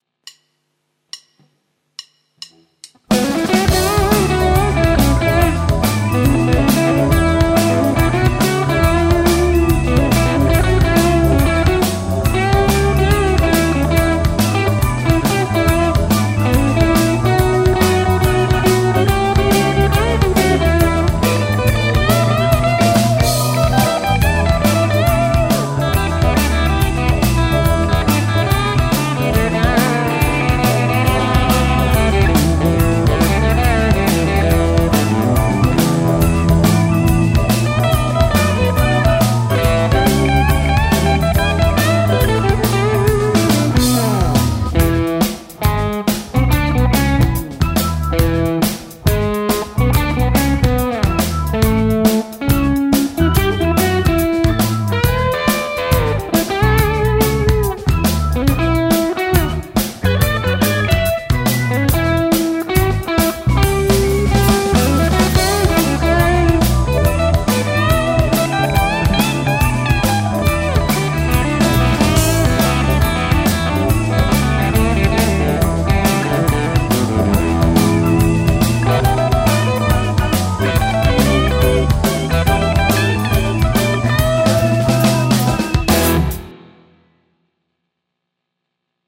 E:sta mennään monille tutulla biisillä.
- soita soolosi annetun taustan päälle